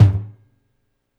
Index of /4 DRUM N BASS:JUNGLE BEATS/KIT SAMPLES/DRUM N BASS KIT 1
FLOOR TOM SHORT 2.wav